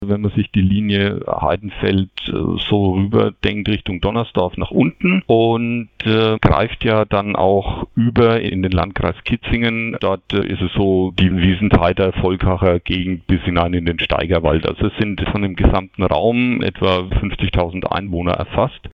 Interview: Bedarfsverkehr in Gerolzhofen - PRIMATON